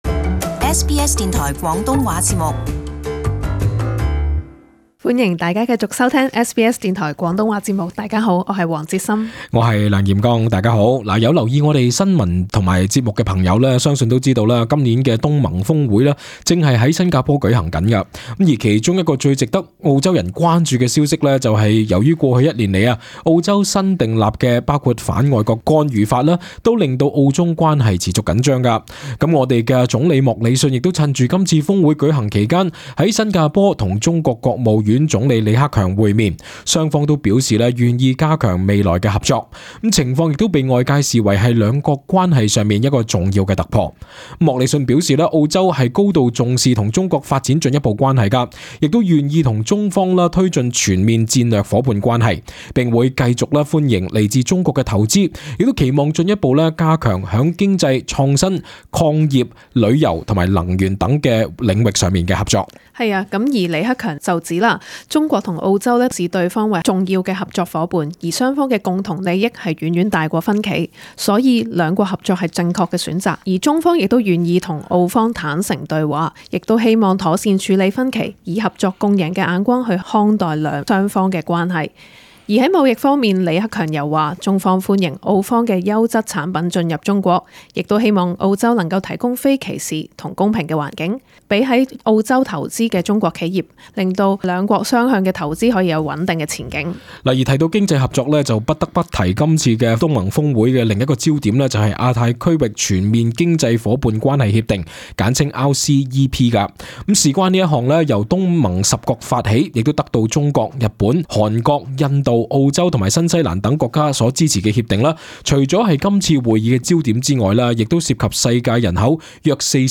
【時事報導】澳中關係有望於東盟峰會破冰？